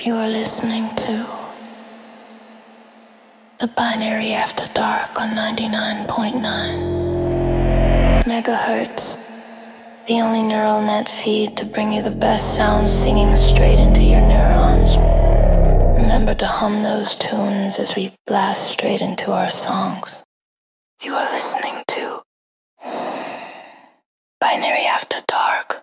"Return from the Ashes" - A brutal fusion of Celtic Folk and Viking Metal.
Featuring deep warrior vocals and driving war percussion.